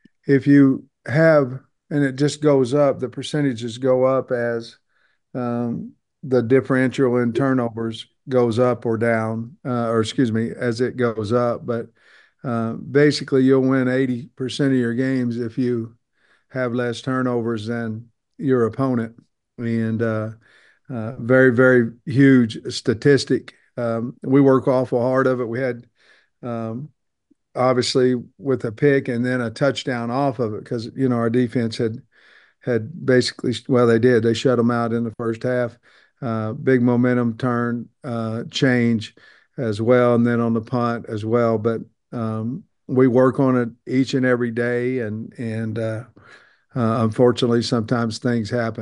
Arkansas head coach Sam Pittman spoke about the turnover differential being key in the contest.